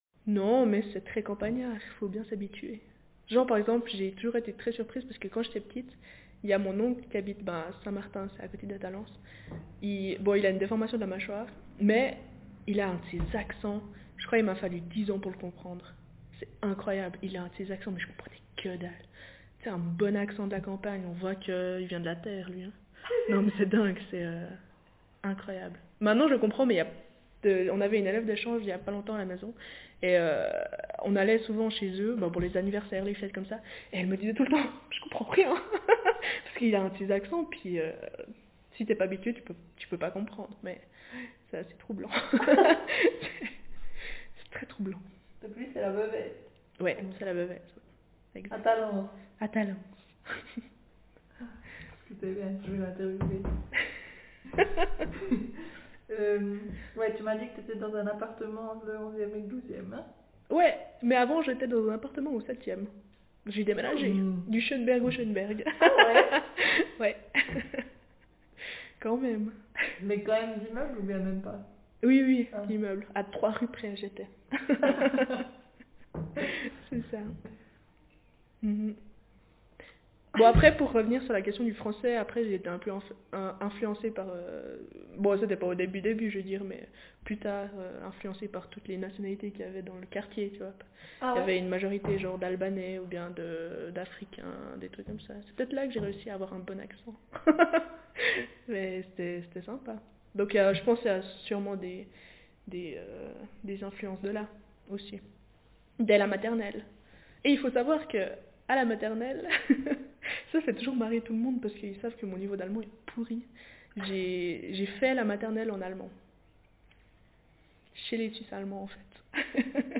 DoReCo - Language French (Swiss)
Speaker sex f Text genre personal narrative